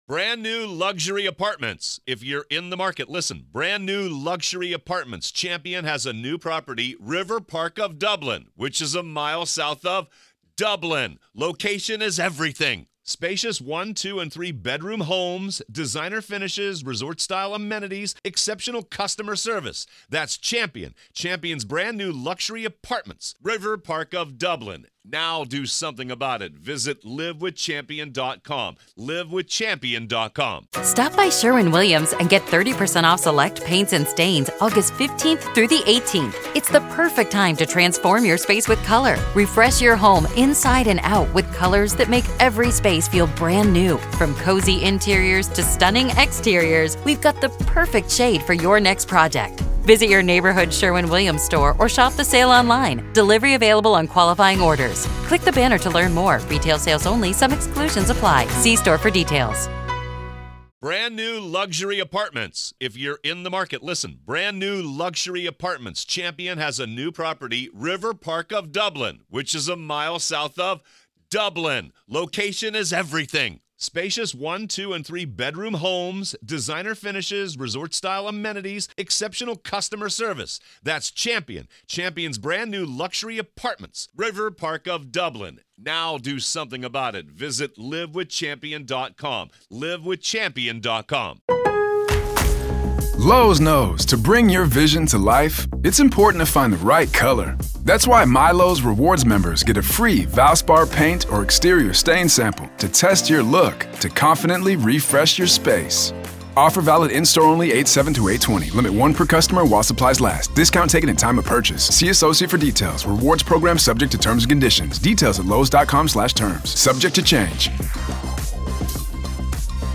True Crime Today | Daily True Crime News & Interviews / Is Chad Daybell Reversing His Decision to Throw Lori Under The Bus?